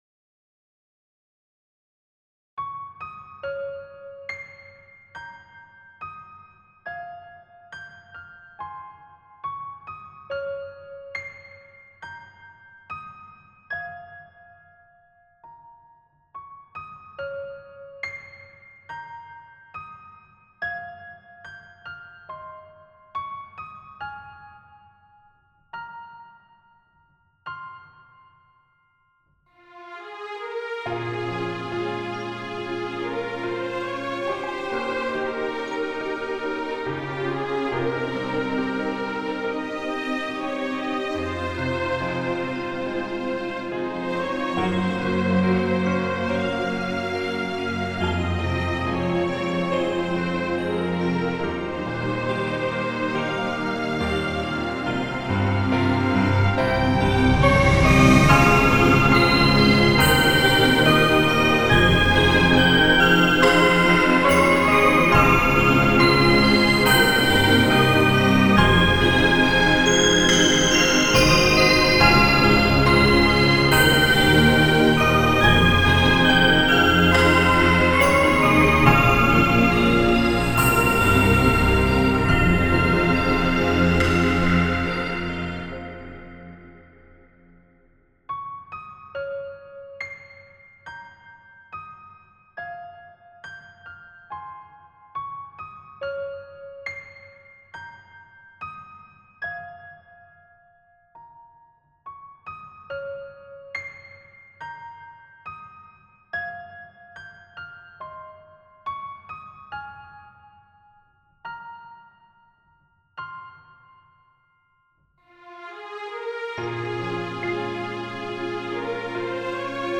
ループ可
イージーリスニング
ピアノ
バイオリン
感動
悲しい